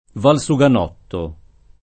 valsuganotto [ val S u g an 0 tto ]